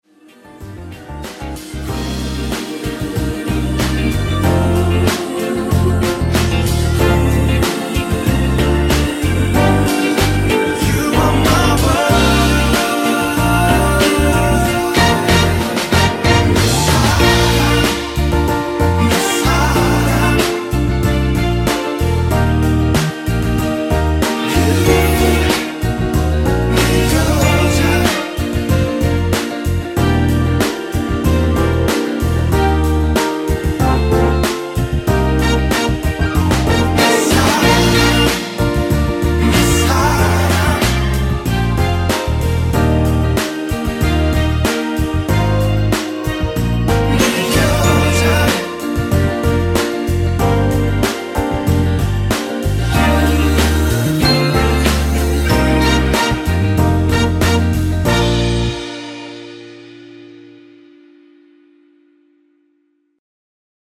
엔딩이 페이드 아웃이라 라이브 하기 좋게 원곡 3분 6초쯤에서 엔딩을 만들었습니다.(미리듣기 참조)
Bb
앞부분30초, 뒷부분30초씩 편집해서 올려 드리고 있습니다.
중간에 음이 끈어지고 다시 나오는 이유는